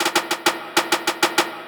Teck-perc (bumbaclot).wav